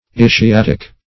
ischiatic - definition of ischiatic - synonyms, pronunciation, spelling from Free Dictionary Search Result for " ischiatic" : The Collaborative International Dictionary of English v.0.48: Ischiatic \Is`chi*at"ic\ ([i^]s`k[i^]*[a^]t"[i^]k), a. (Anat.)
ischiatic.mp3